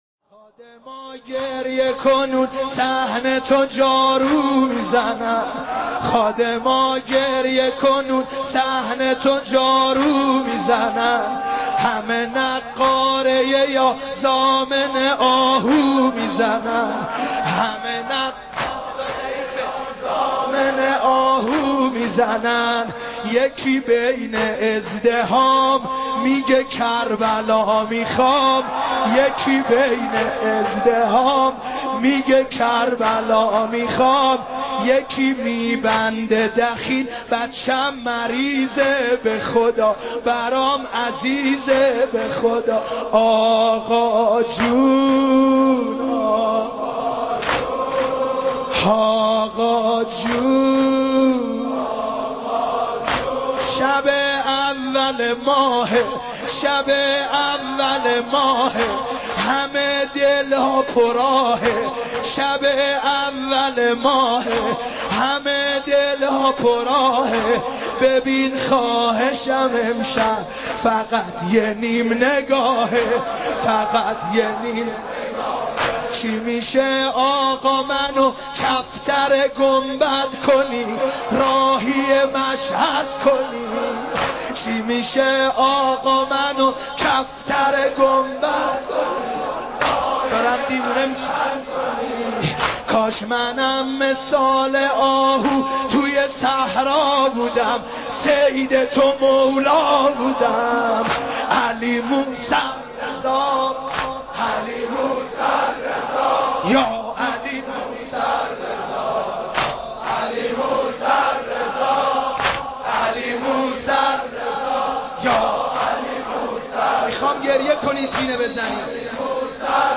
مداحی جدید شهادت امام رضا (ع)